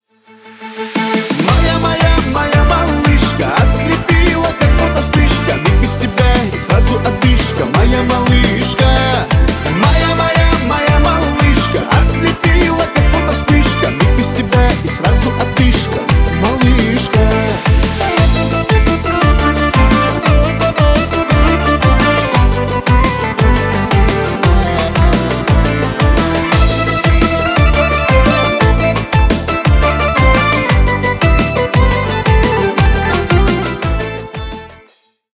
Поп Музыка
кавказские # грустные